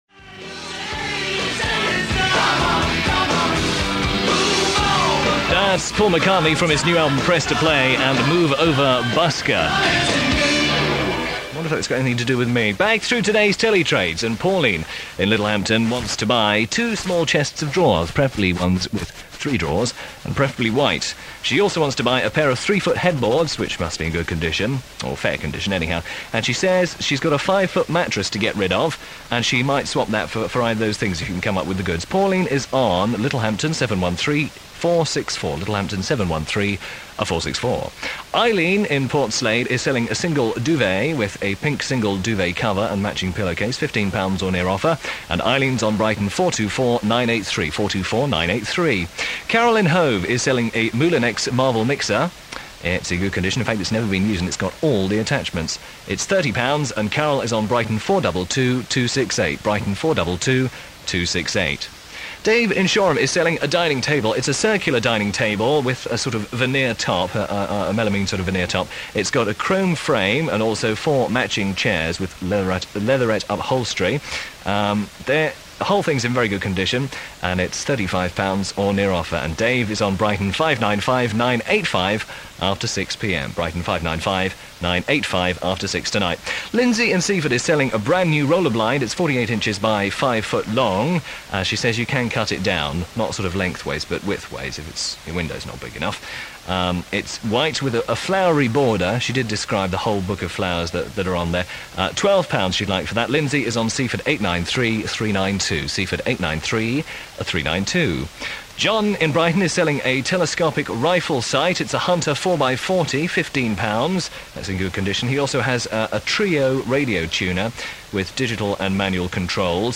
Air Checks